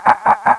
cranking.wav